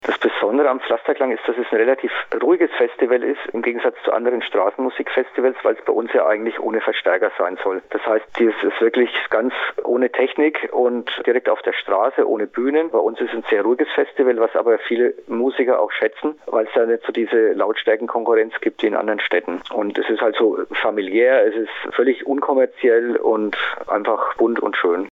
Interview: Pflasterklang in Schweinfurt - PRIMATON